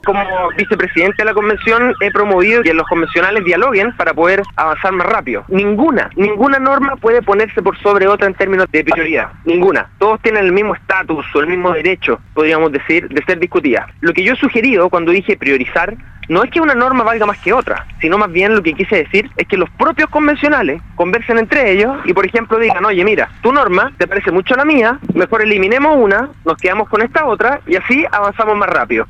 Una férrea defensa al trabajo que han realizado hasta el momento en la Convención Constitucional formuló el actual vicepresidente de la mesa directiva y constituyente por el distrito 26, Gaspar Domínguez, en entrevista con Radio Sago. En primer término, se refirió al marco de respeto que debe primar, considerando los insultos emitidos por la convencional Teresa Marinovic en la previa de un punto de prensa tras salir de la sala junto a Katherine Montealegre y Rosario Cantuarias por estar en desacuerdo de la modalidad de presentación de propuestas populares en bloque en lugar de individuales.